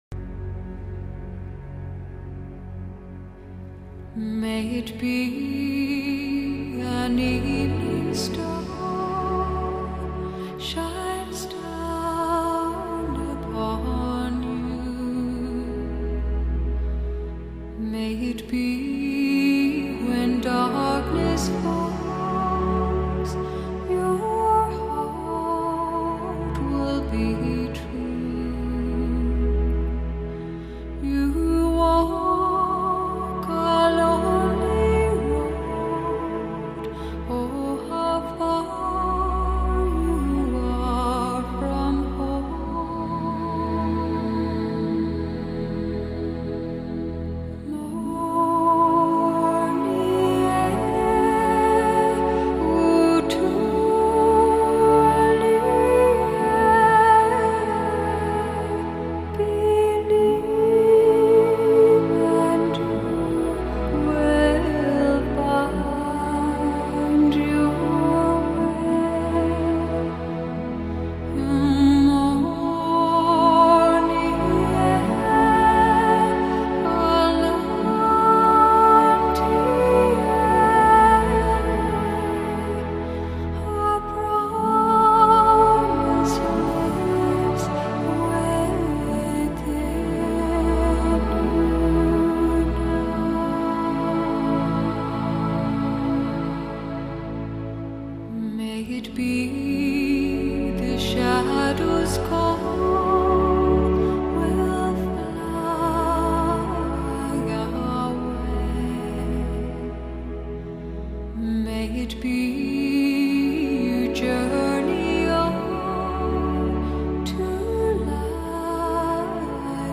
本碟声音最靓，人声和音乐都很润耳。